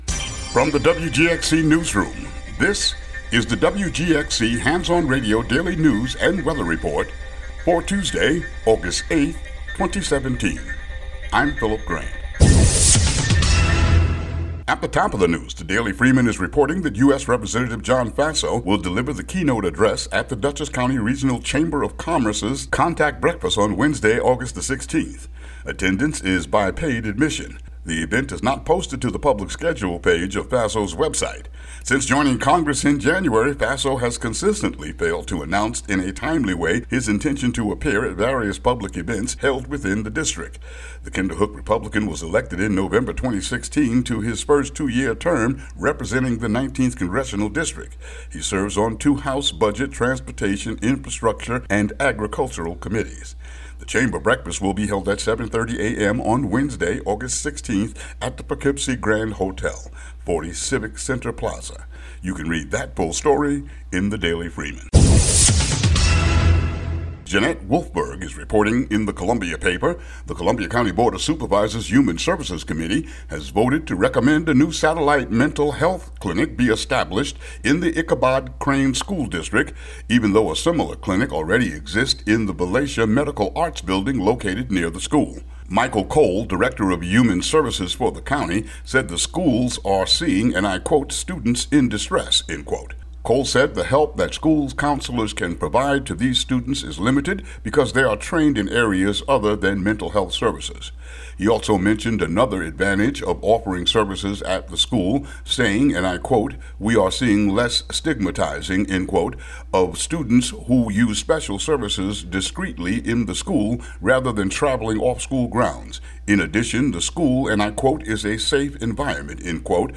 WGXC daily headlines for August 8, 2017.